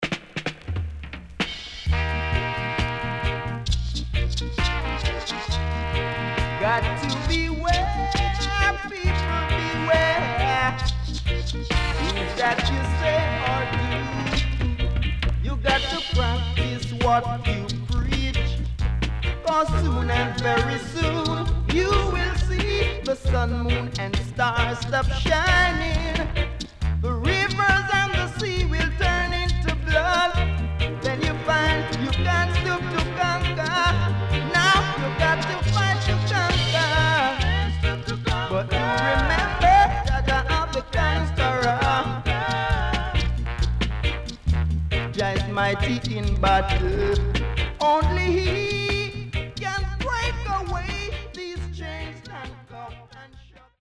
SKA,ROCKSTEADY,REGGAE,ROOTS,DANCEHALL,SOUL